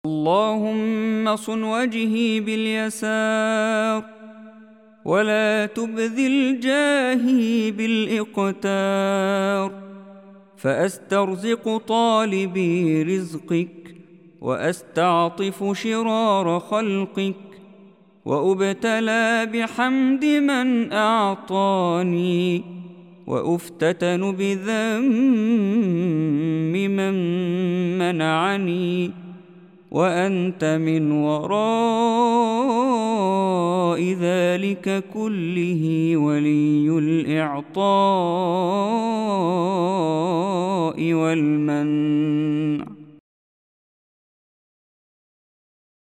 دعاء خاشع ومناجاة يتضمن الاعتراف بنعم الله والتوكل عليه في طلب الرزق، مع الحمد والشكر على العطاء والصبر على المنع. يعبر عن حالة أنس وافتقار تام إلى الله تعالى في كل الأحوال.